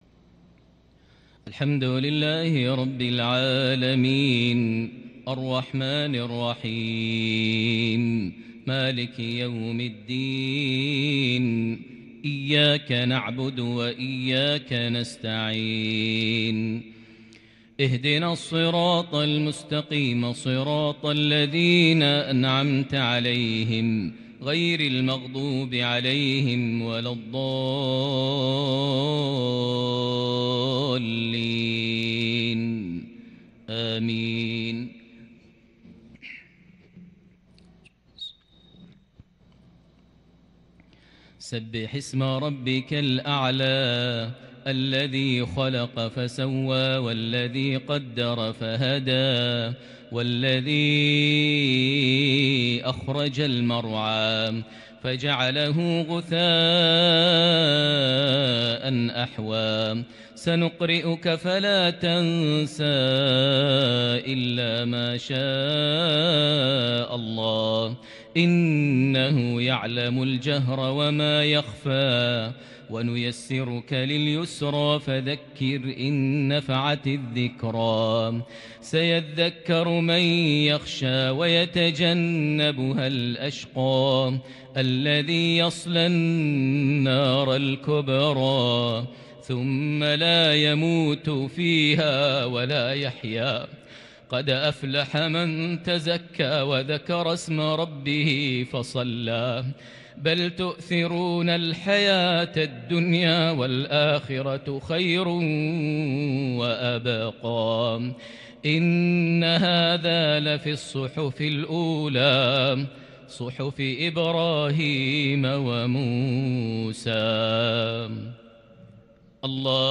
تلاوة خاشعة لسورتي الأعلى - الغاشية | صلاة الجمعة 6 ربيع الأول 1442هـ > 1442 هـ > الفروض - تلاوات ماهر المعيقلي